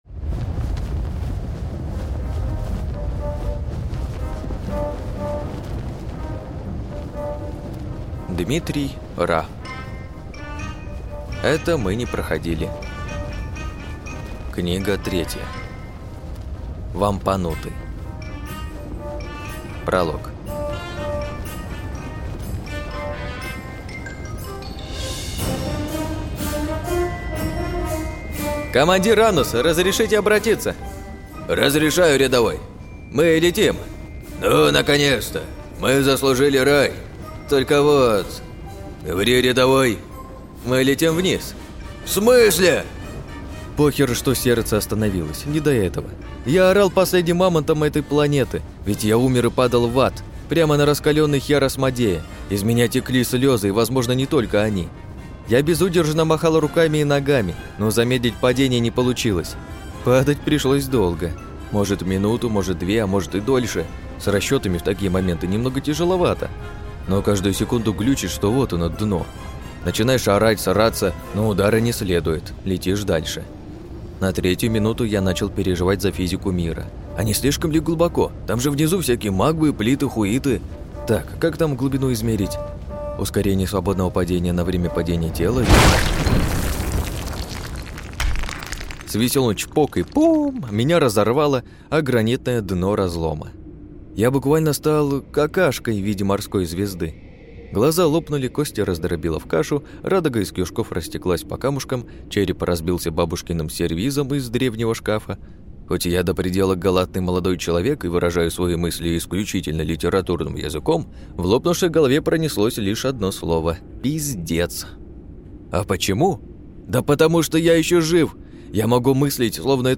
Аудиокнига Вампанутый. Том 3 | Библиотека аудиокниг